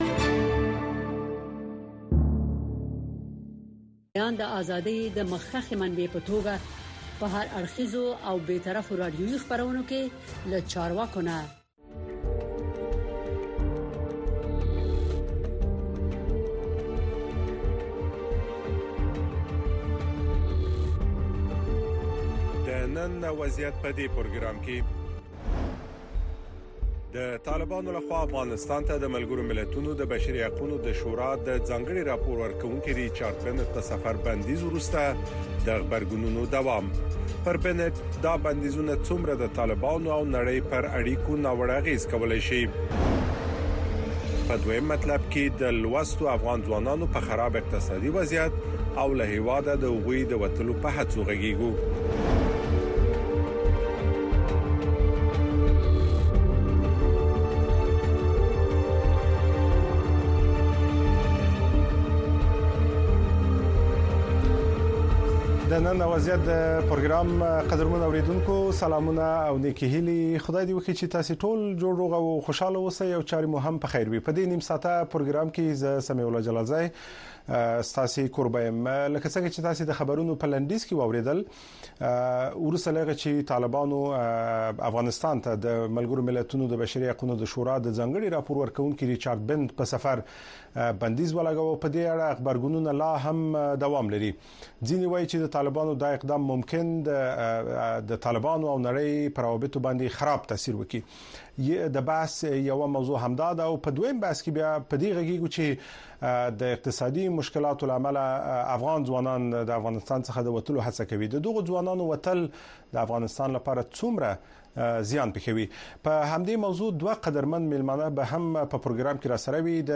د نن او وضعیت په خپرونه کې د افغانستان د ټولنیز او فرهنګي وضعیت ارزونه ددې خپرونې له میلمنو څخه اورئ. دغه خپرونه هره شپه د ٩:۳۰ تر ۱۰:۰۰ پورې په ژوندۍ بڼه ستاسې غږ د اشنا رادیو د څپو او د امریکا غږ د سپوږمکۍ او ډیجیټلي خپرونو له لارې خپروي.